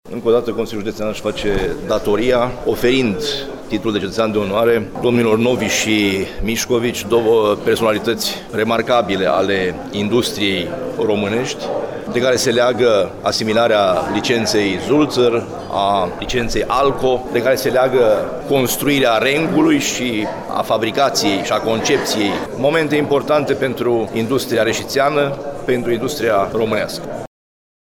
a spus preşedintele Administraţiei judeţene, Sorin Frunzăverde: